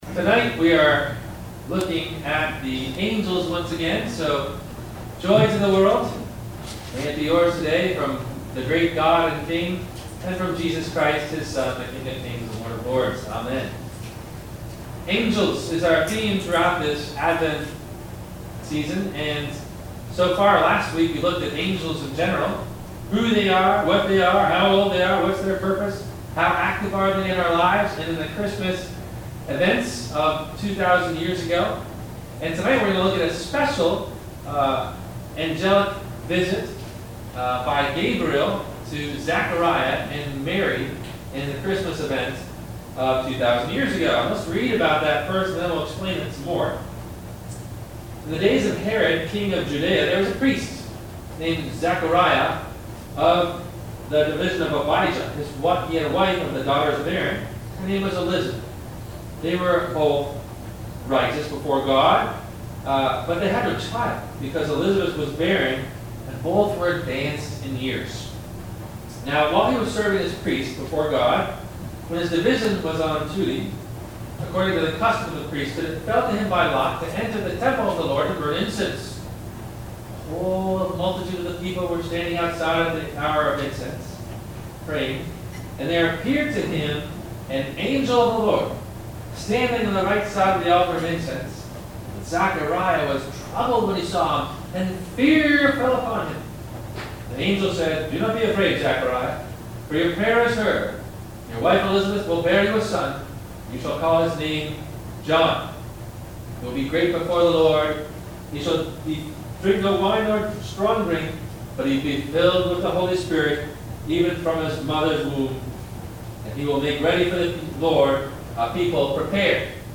Gabriel's Amazing Words to Zechariah & Mary - Advent Wednesday 2 - Sermon - December 07 2016 - Christ Lutheran Cape Canaveral